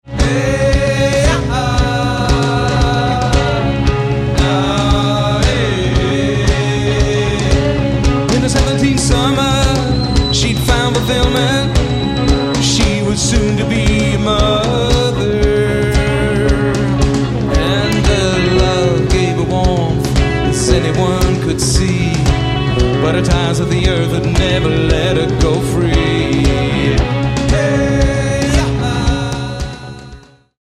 Album Notes: Recorded live 2000-2003